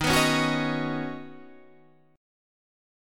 D#m7#5 chord